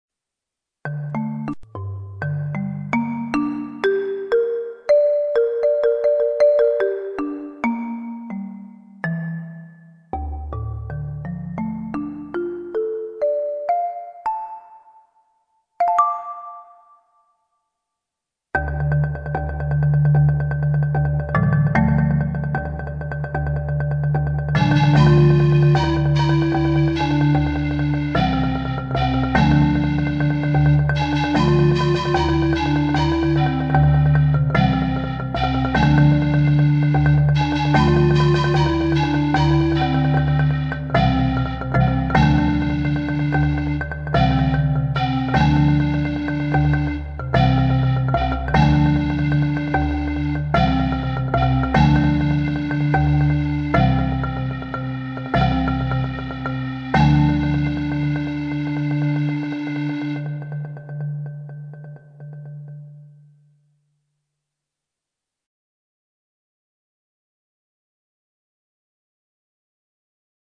It was here that we decided that in the interest of time the children would play non-tuned percussion instruments (adding an African sound) and I would create computerized accompaniments.
Accompaniment, MP3 Listen to the audio file